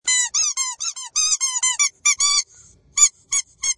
Download Squeaky Noise sound effect for free.
Squeaky Noise